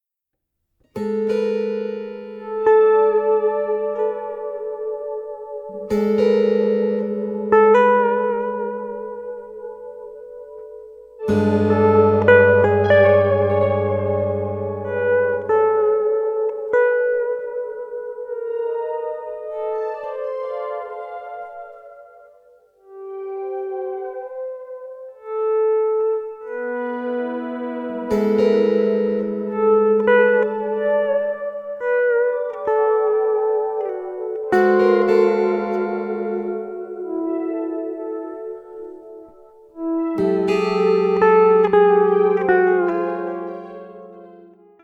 Electric guitar, Electric Baritone guitar